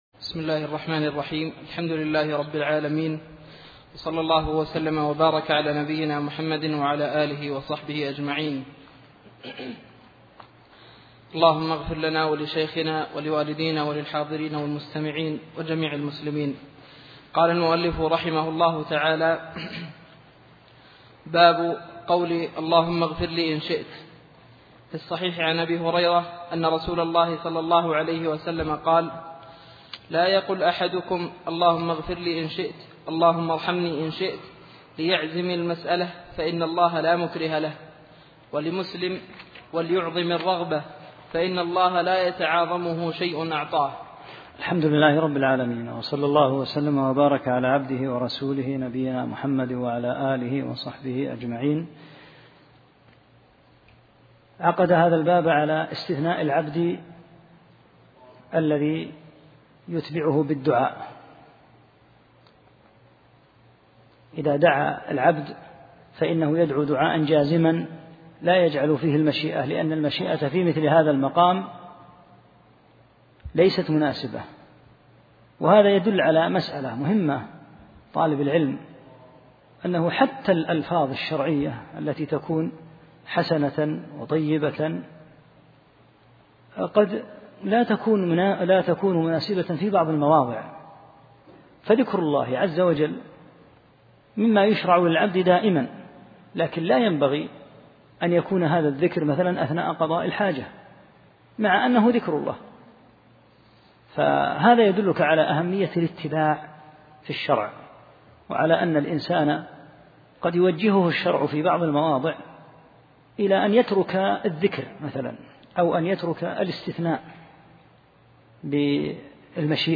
16 - الدرس السادس عشر